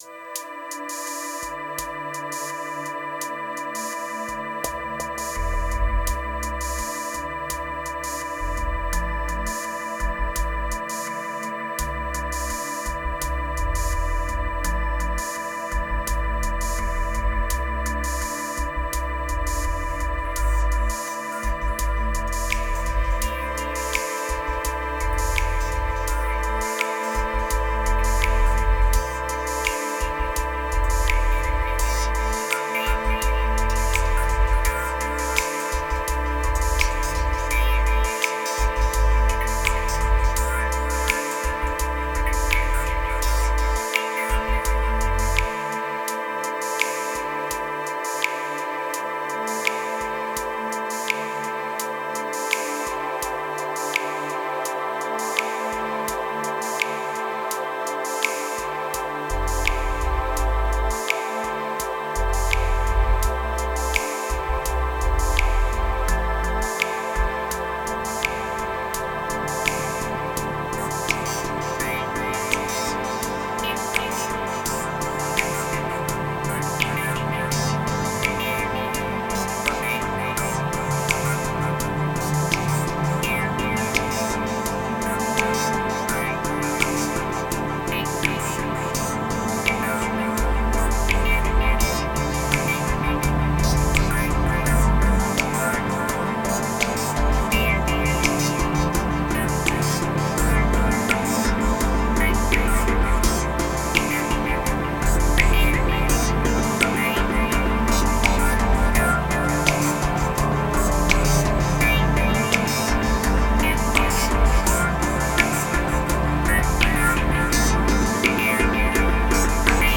605📈 - 91%🤔 - 84BPM🔊 - 2021-10-31📅 - 831🌟